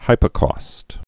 (hīpə-kôst)